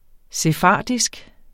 Udtale [ seˈfɑˀdisg ]